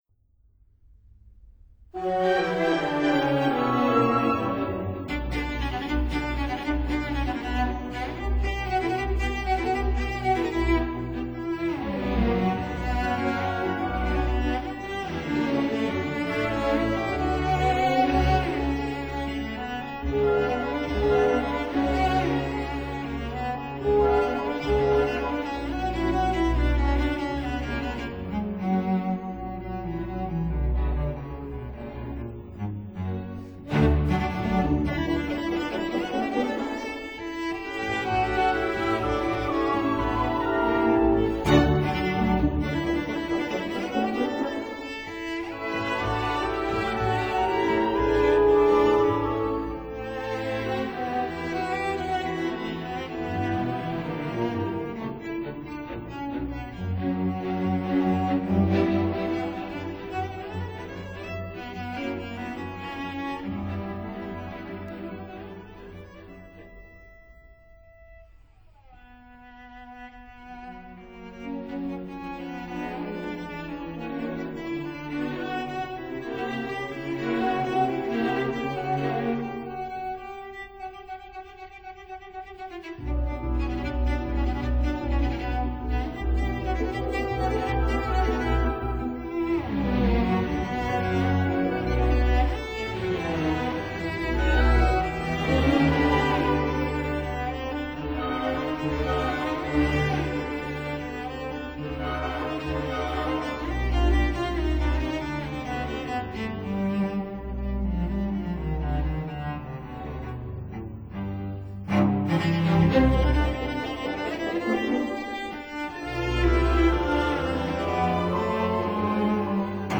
for cello and orchestra
Cello Concerto